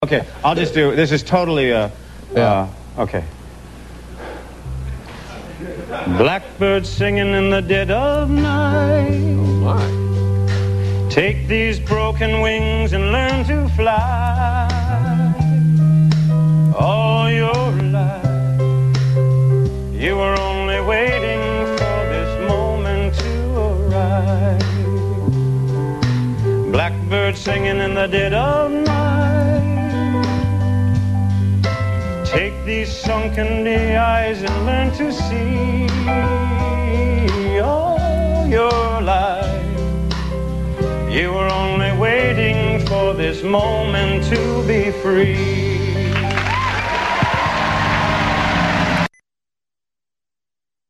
Music